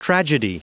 Prononciation du mot tragedy en anglais (fichier audio)
Prononciation du mot : tragedy
tragedy.wav